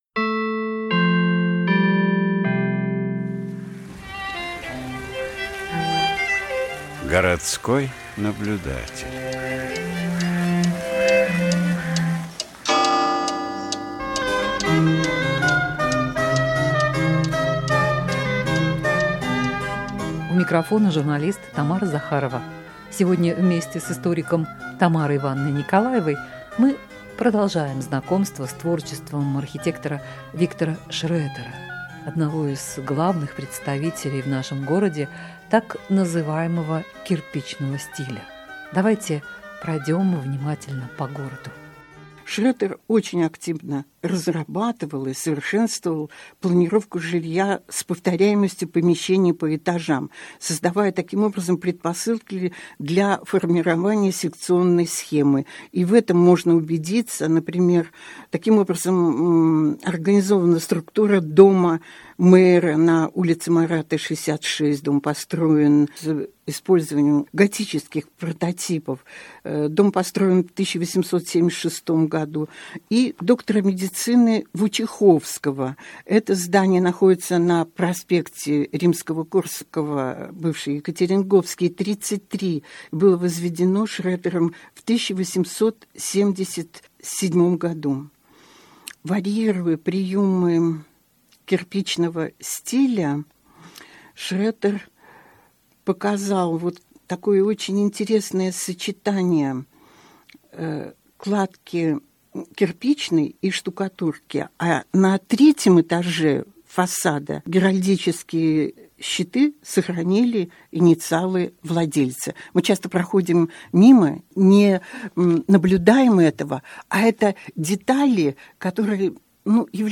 Архитектор Виктор Шретер. Рассказывает историк архитектуры